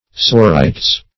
Sorites \So*ri"tes\, n. [L., from Gr. swrei`ths (sc.